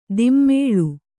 ♪ dimmēḷu